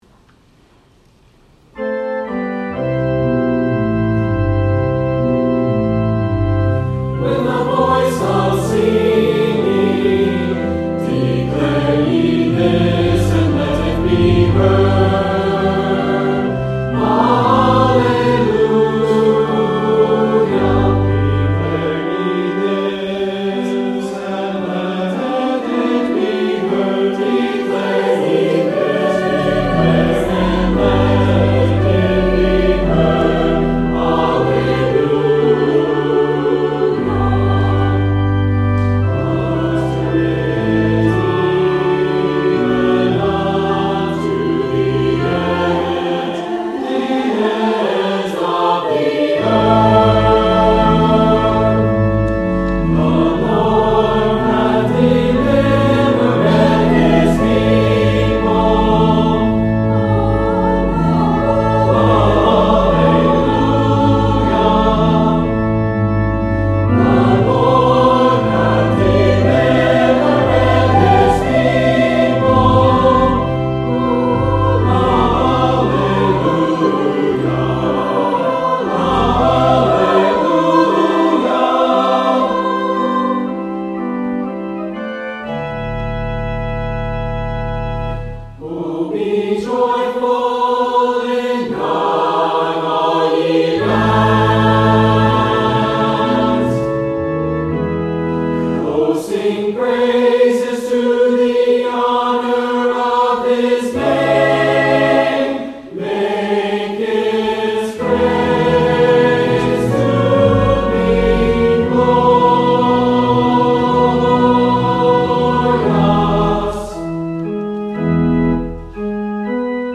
Offertory: Chancel Choir